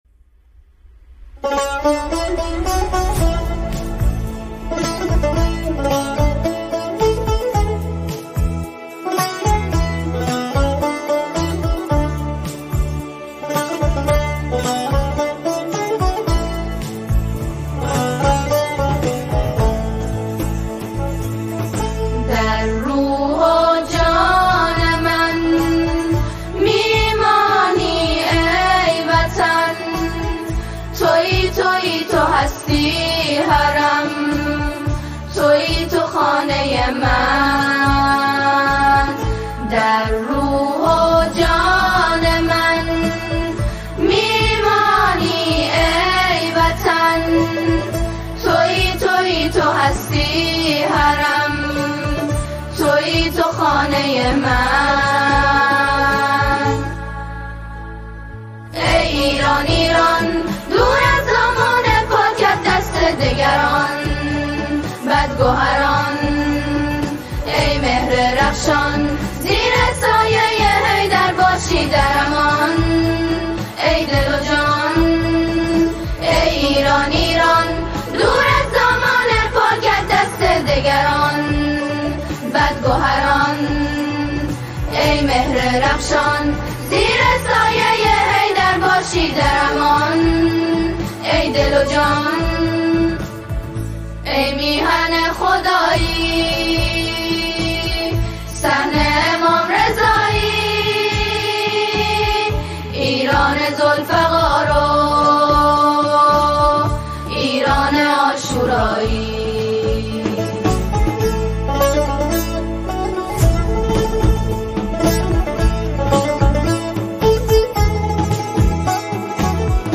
یک اثر ملی-مذهبی صمیمی و عمیق